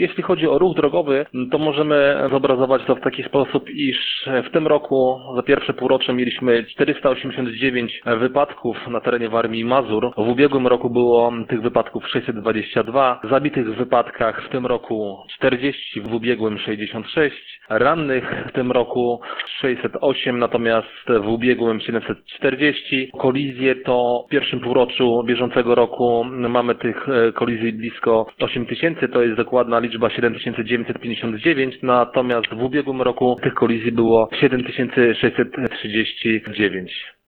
Wiadomości